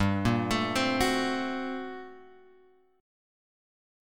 GmM11 chord {3 1 x 2 1 2} chord